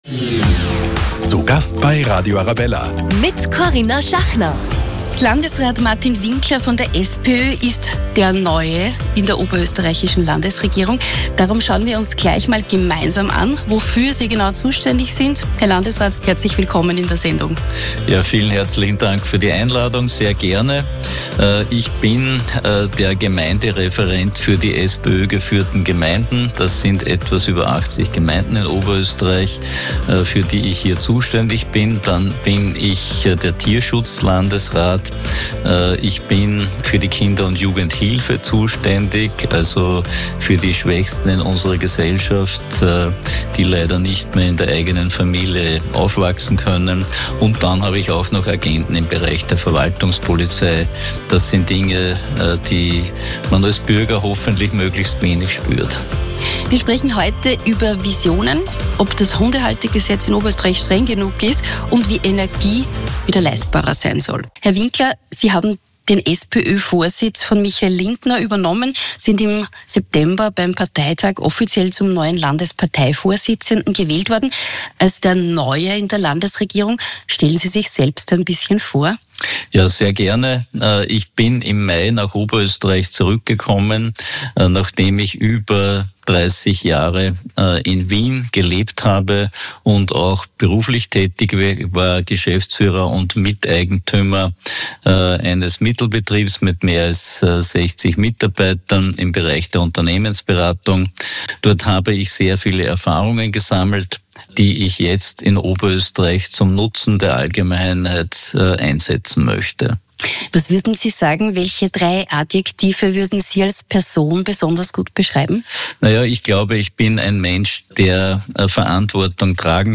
Zu Gast bei Radio Arabella: Landesrat Martin Winkler